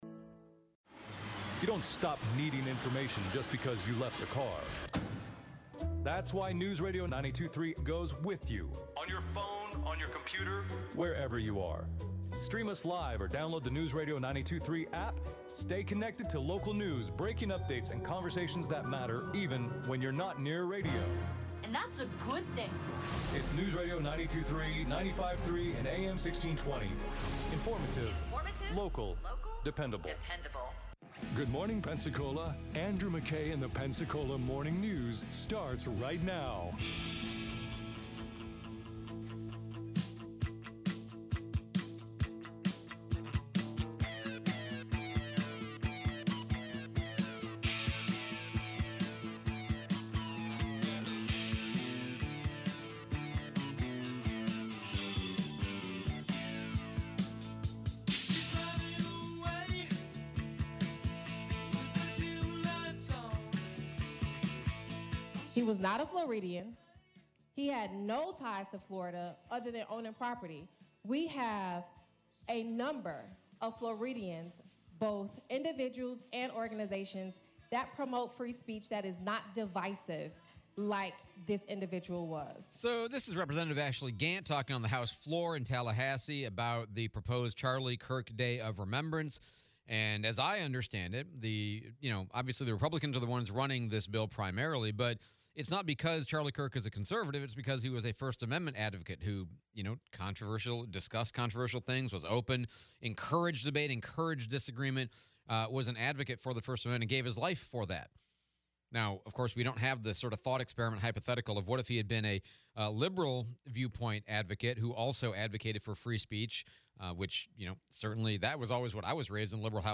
Charlie Kirk Day and road designation, Ryan Jenkins Farms interview